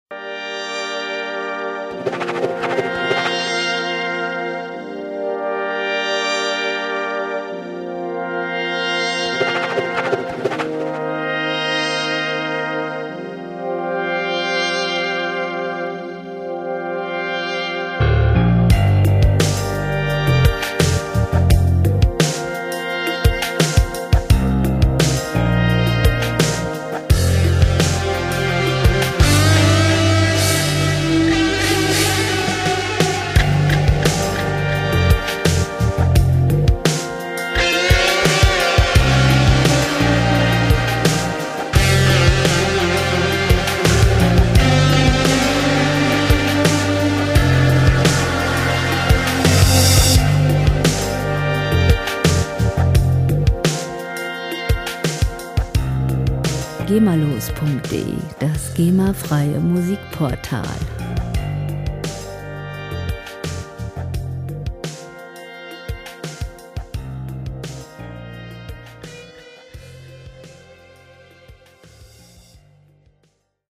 Hintergrundmusik - Großstadt Impressionen
Musikstil: Psychedelic Rock
Tempo: 86 bpm
Tonart: G-Moll
Charakter: emotional, eindringlich